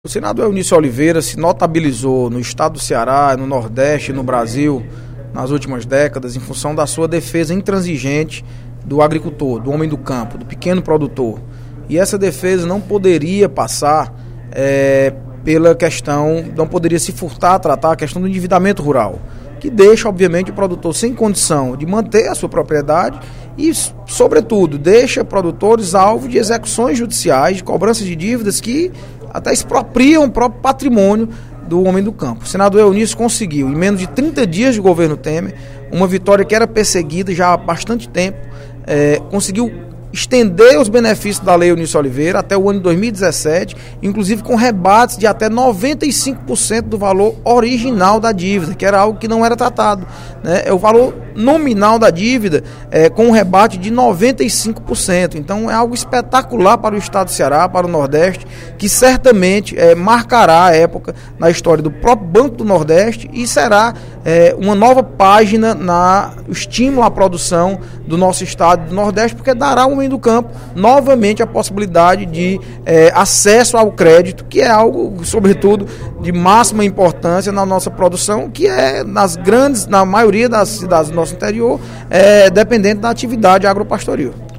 O deputado Audic Mota (PMDB) fez pronunciamento nesta quarta-feira (15/09), durante o segundo expediente da sessão plenária, para elogiar o governo interino de Michel Temer, que, segundo ele, editou medida provisória proposta pelo senador Eunício Oliveira (PMDB/CE) que permite a renegociação de dívidas dos agricultores com o Banco do Nordeste.